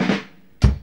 FILL 1   120.wav